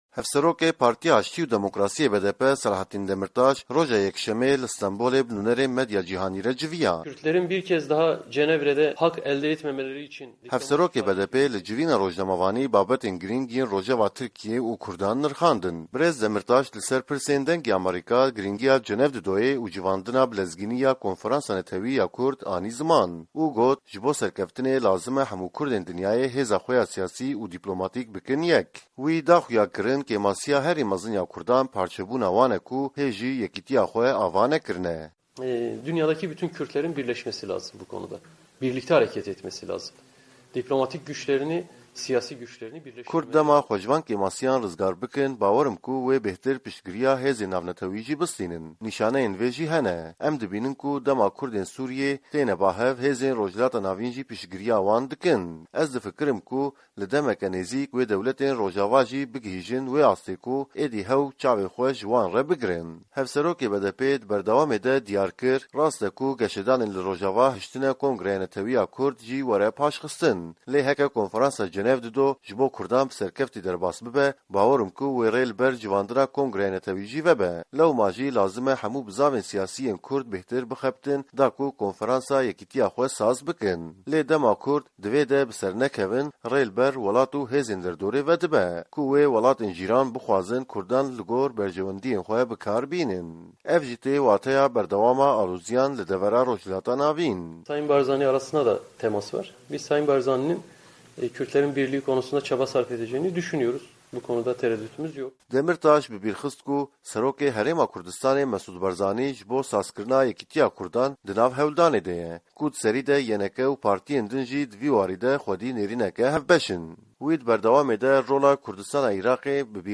Raport